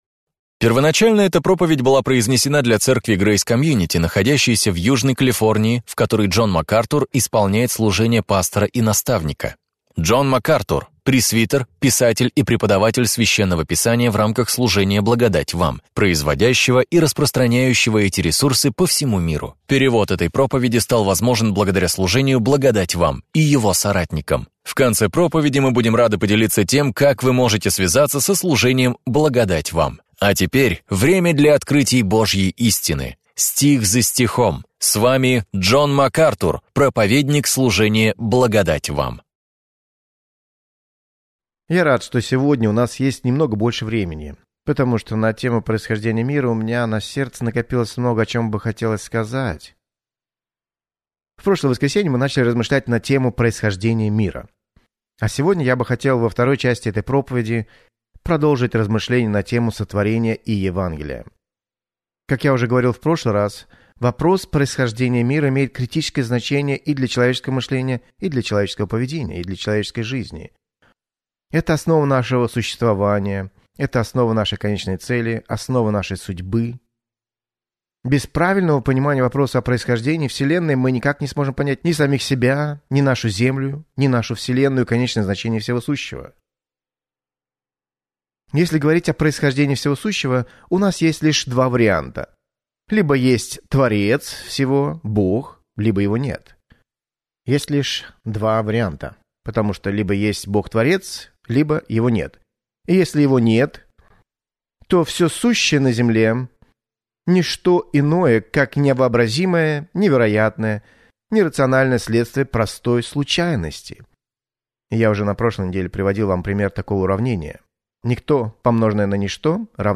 В своей проповеди «Битва за начало» Джон Макартур раскрывает суть этих споров